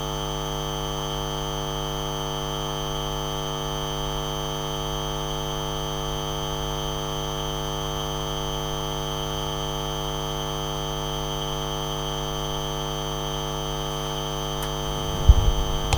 synthetic telepathy recording audacity 21 aug 2015
I probably started following it as I could barely hear it in the wireless headphone that was NOT on my head but on the table next to the recorder which was recording.  This somewhat explains how this phenomenon works: it generates a household buzz that can sometimes show up as tinnitus in the head/ears.
Notice that there is a sound frequency at 20,000 hertz.